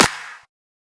edm-clap-54.wav